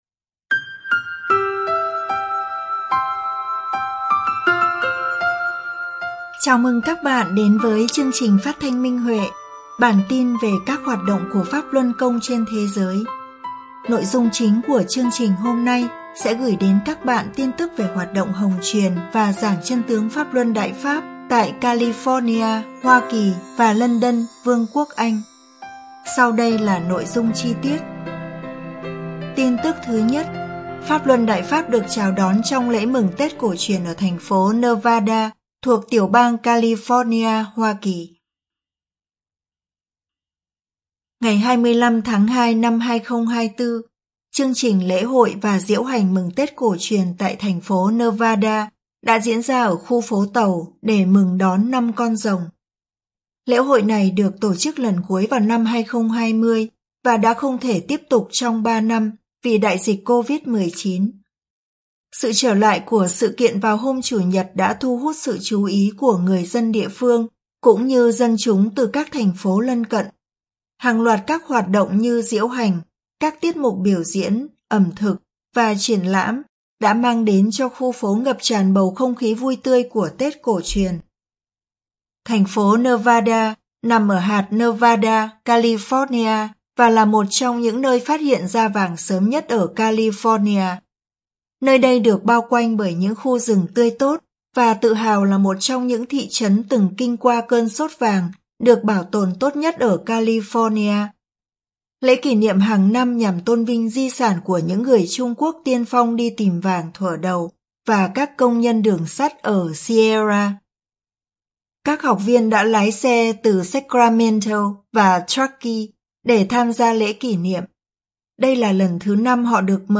Chương trình phát thanh số 135: Tin tức Pháp Luân Đại Pháp trên thế giới – Ngày 04/03/2024